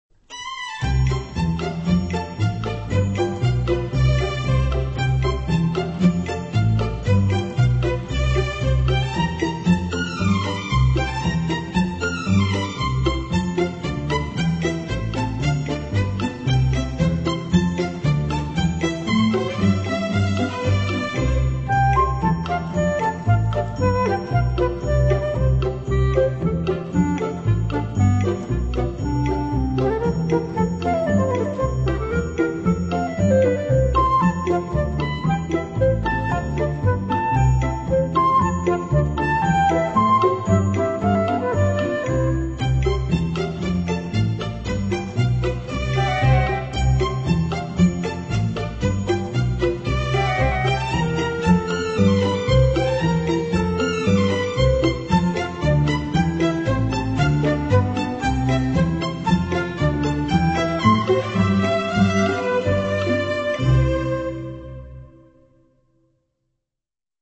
a violin and piano piece